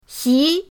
xi2.mp3